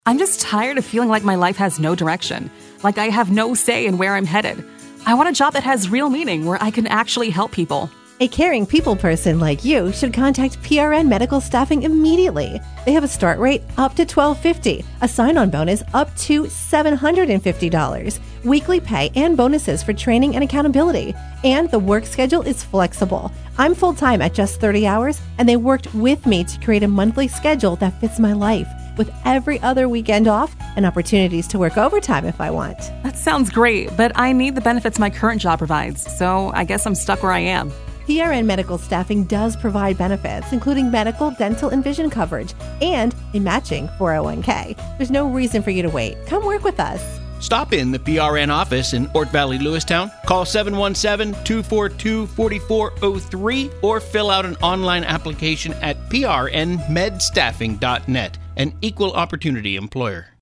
PRN-REVISED-Recruitment-Ad_4Oct2022.mp3